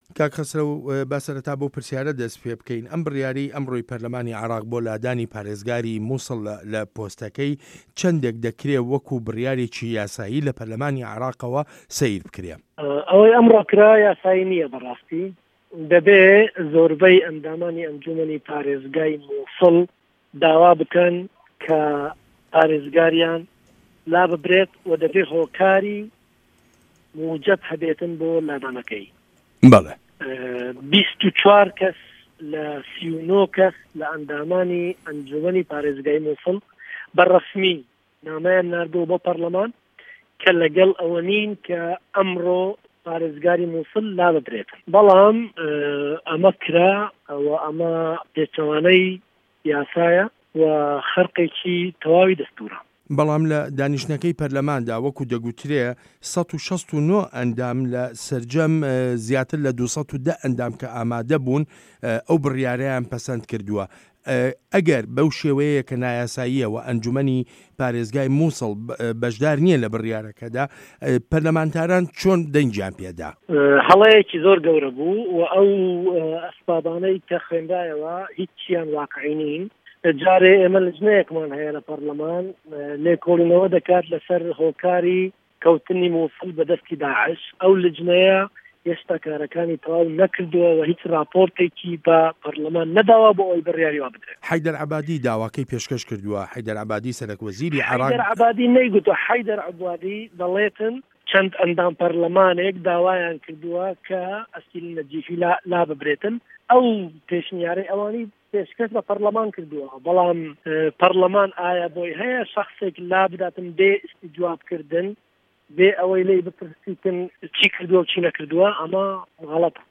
وتووێژ له‌گه‌ڵ خه‌سره‌و گۆران